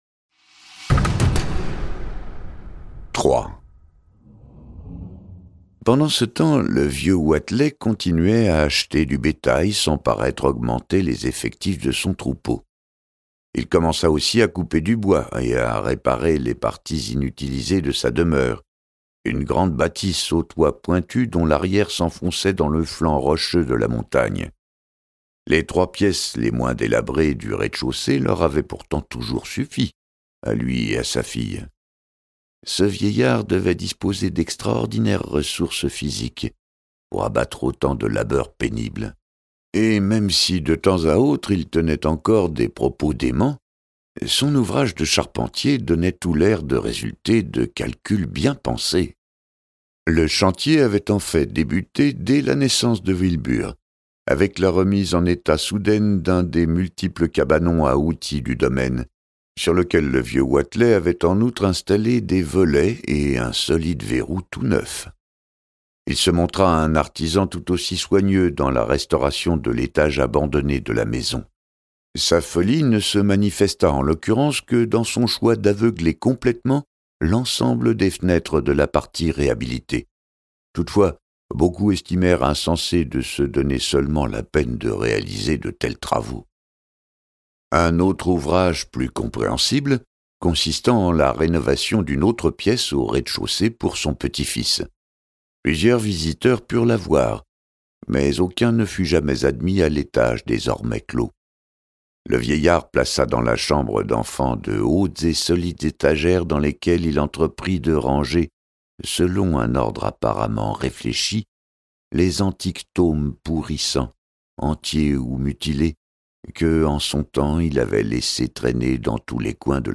Le mythe de Cthulhu n'a jamais été aussi réel…Ce livre audio est interprété par une voix humaine, dans le respect des engagements d'Hardigan.